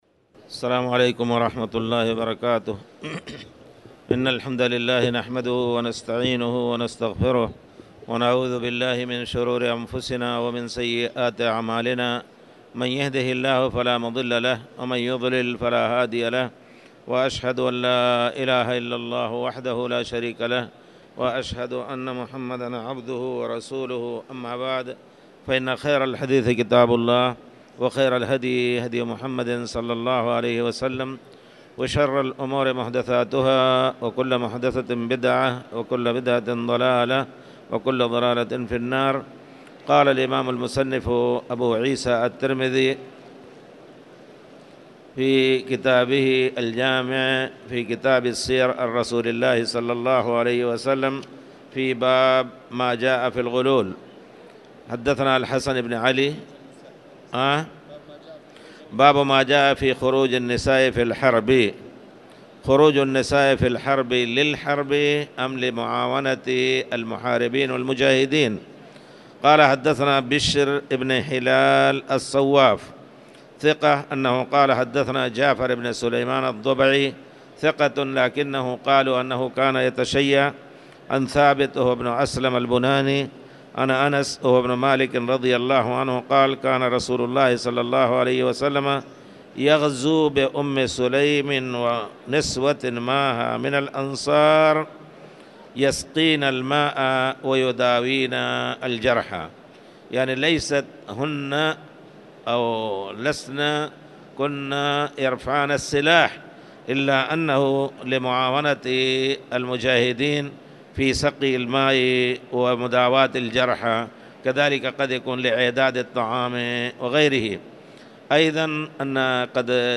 تاريخ النشر ١٨ رجب ١٤٣٨ هـ المكان: المسجد الحرام الشيخ